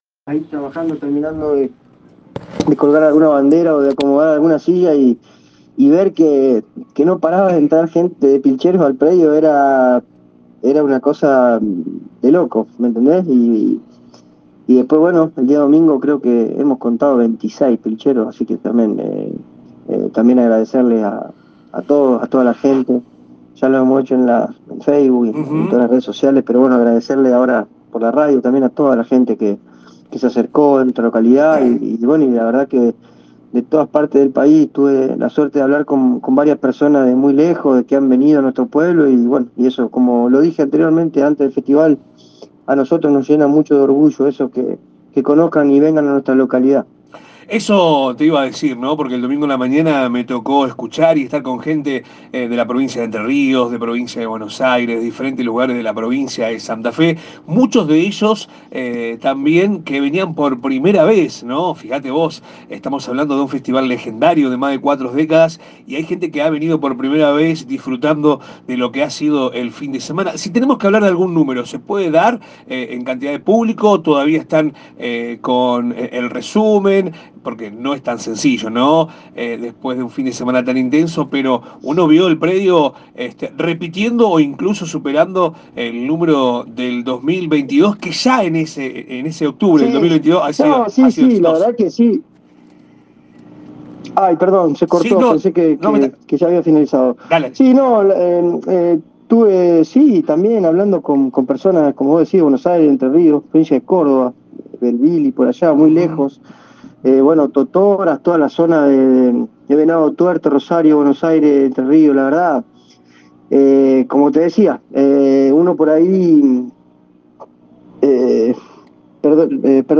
dialogó con nuestra emisora y dejó estos conceptos :
WhatsApp-Audio-2023-10-10-at-11.25.23.ogg